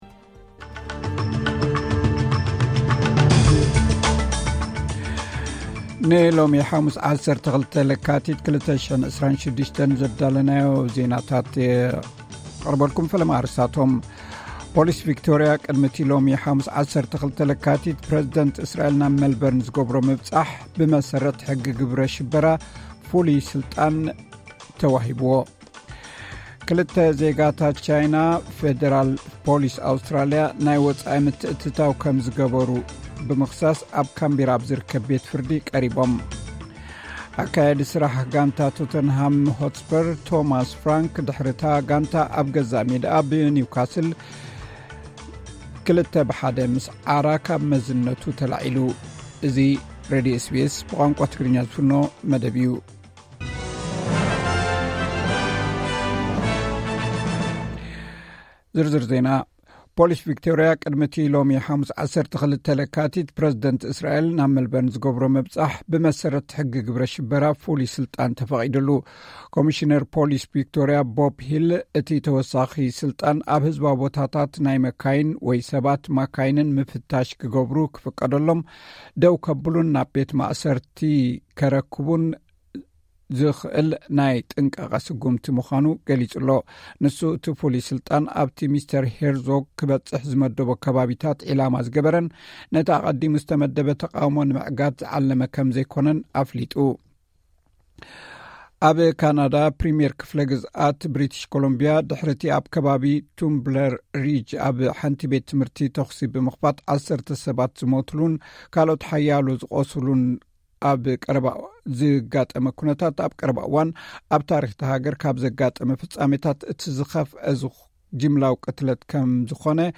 ዕለታዊ ዜና ኤስ ቢ ኤስ ትግርኛ (12 ለካቲት 2026)